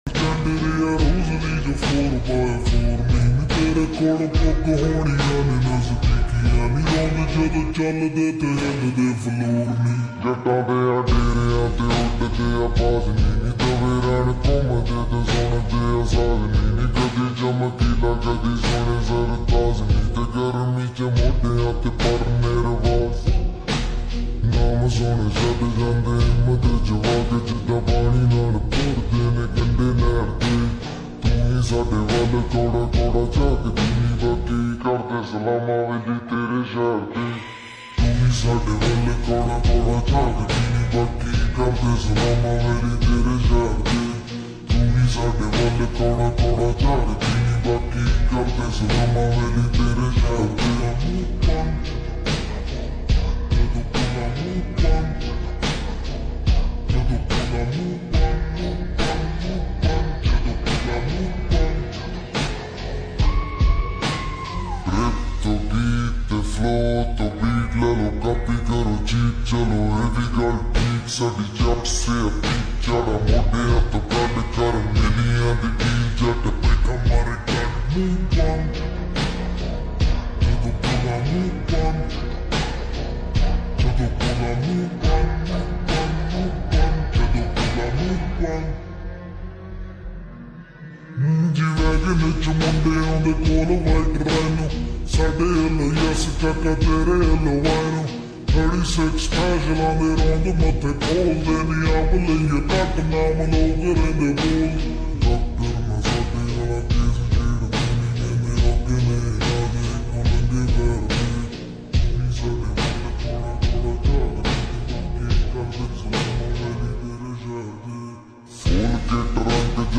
SLOWED AND REVERB FULL SONG PUNJABI ULTRA HD SLOWED SONG